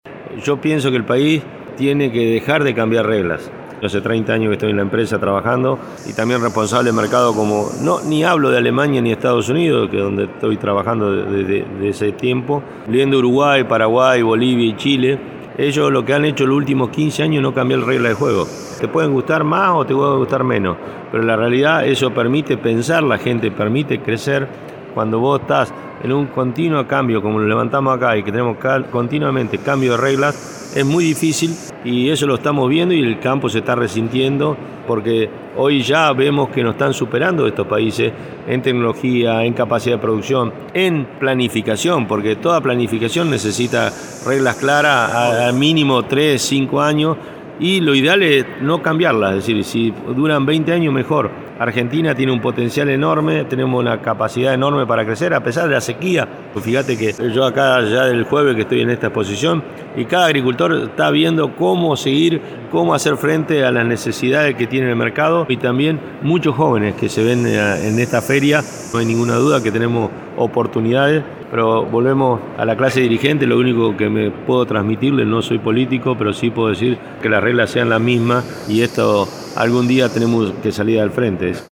dialogó con El Campo Hoy en Palermo.